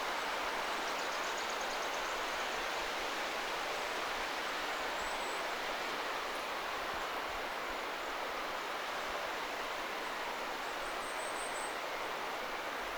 Onko se puukiipijälintu.
Huomaa kiihkeän ääntelyn jälkeen kuuluvat
hiljaisemmat äänet.